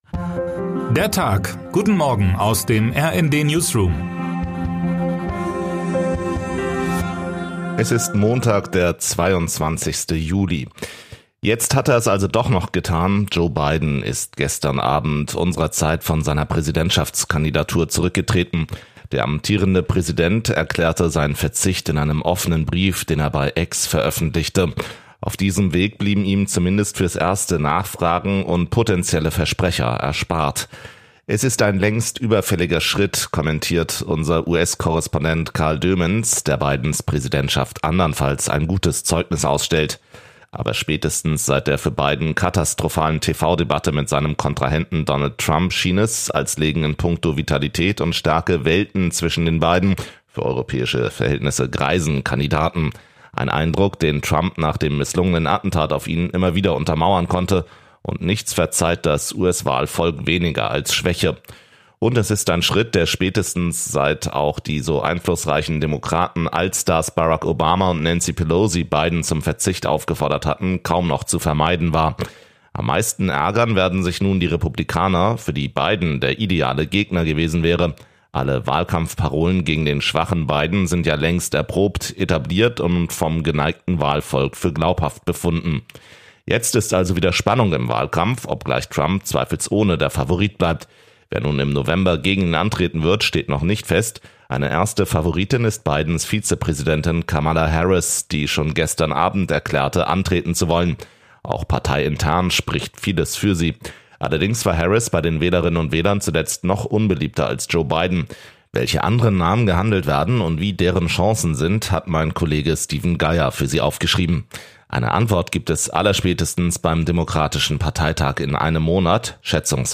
Guten Morgen aus dem RND-Newsroom
Nachrichten